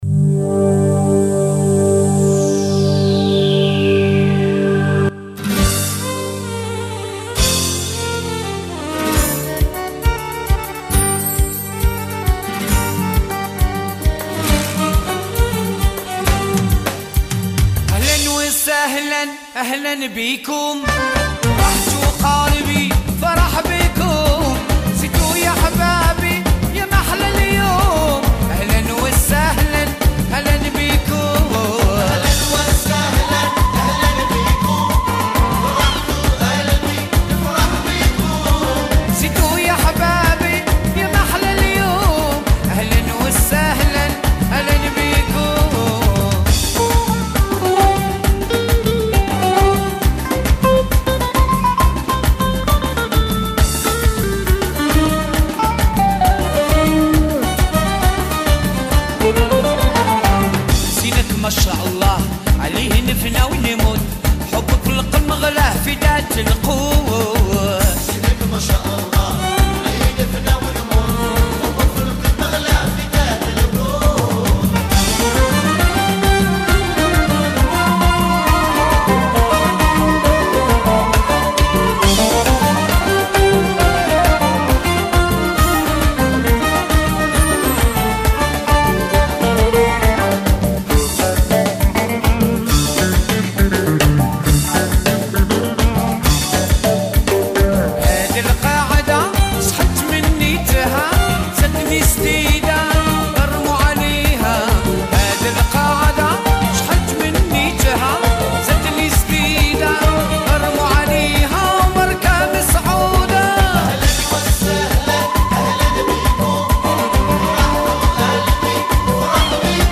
musique d’ouverture de la Mimouna